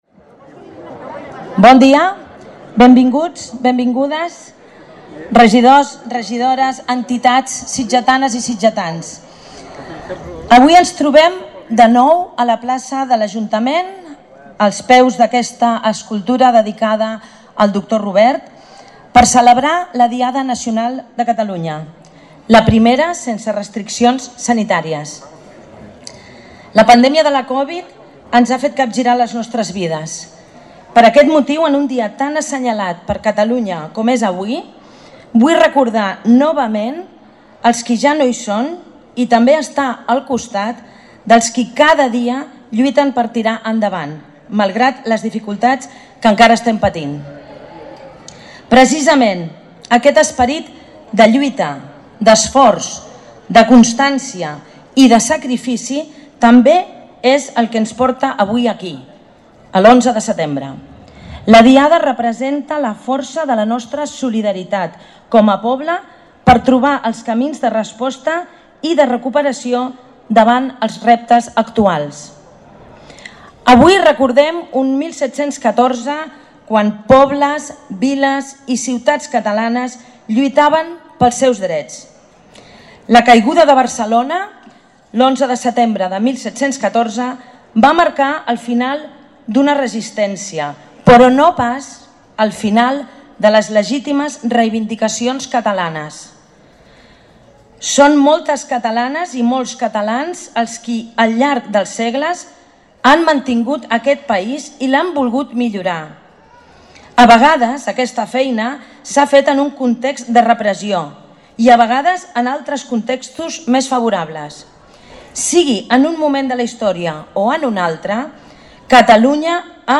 Bona diada! Discurs institucional de l’11 de setembre
Sense cap restricció, però amb menys presència de públic que en anys anteriors, aquest migdia s’ha tornat a celebrar l’acte institucional de la Diada Nacional de Catalunya.